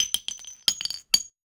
weapon_ammo_drop_12.wav